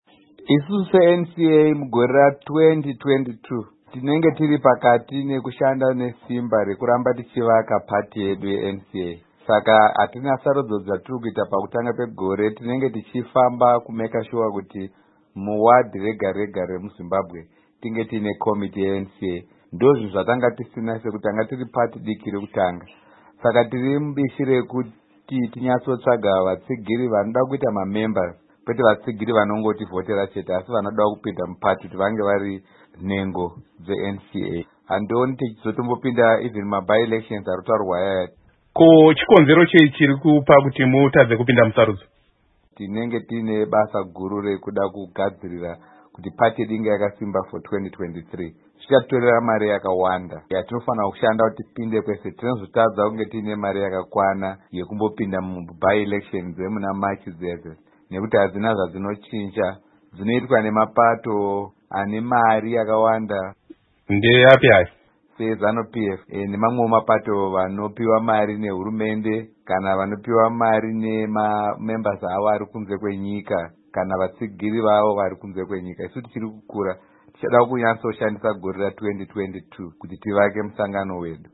Hurukuro naMuzvinafundo Lovemore Madhuku